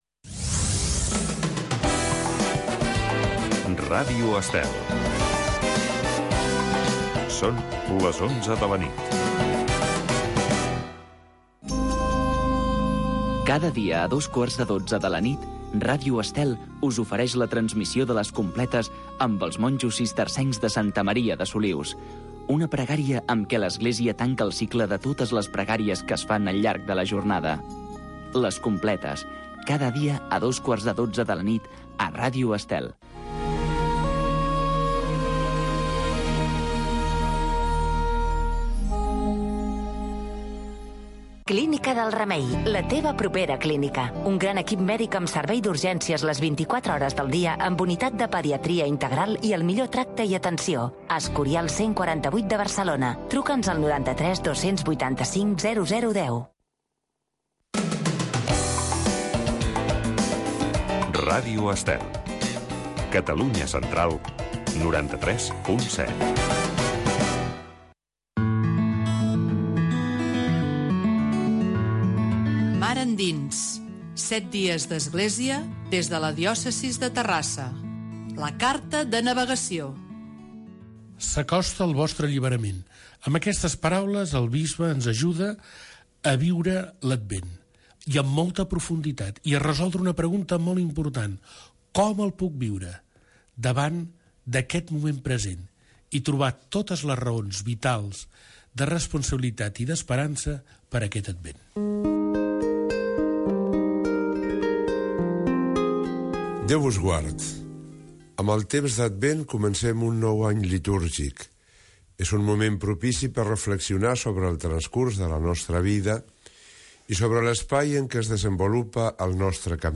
Mar endins. Magazine d’actualitat cristiana del bisbat de Terrassa.